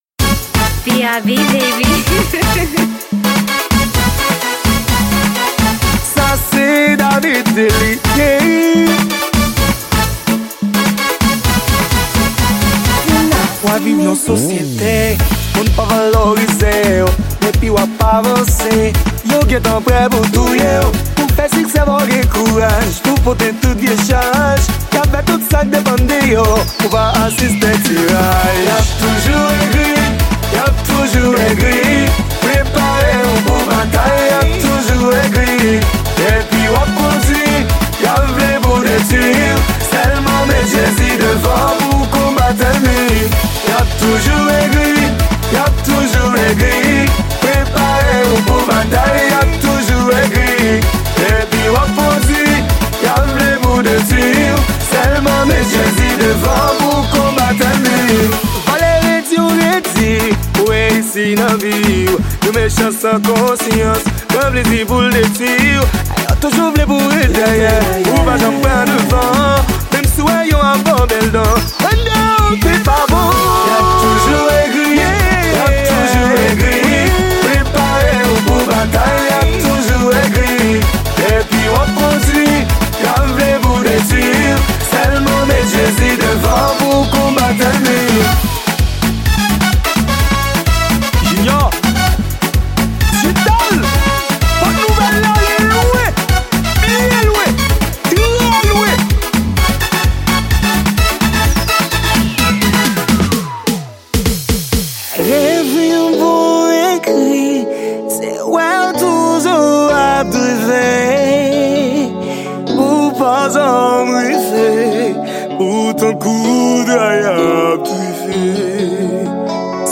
Genre: World.